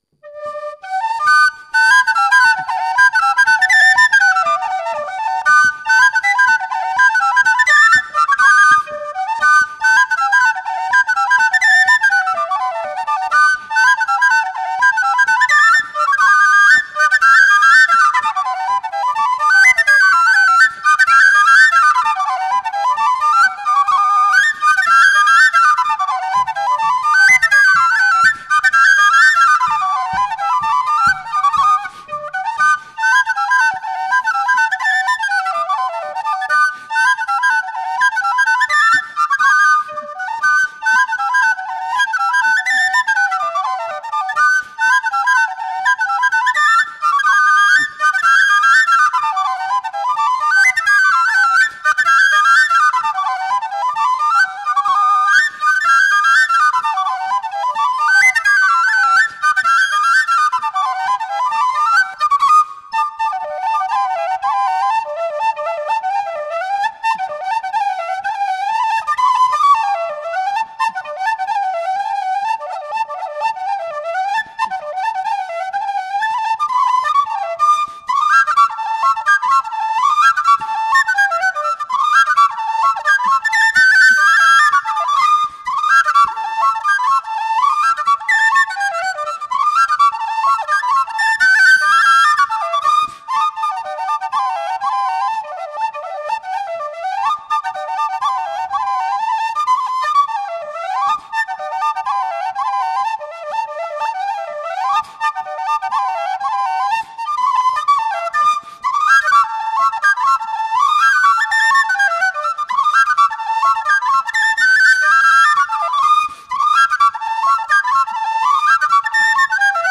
Maquette réalisée en Octobre 2005
aux studios du Micro-Bleu - RUCA (Côtes du Nord)
batterie
guitare basse
whistles
accordéon diatonique
REELS
Deux petits reels irlandais.
reels.mp3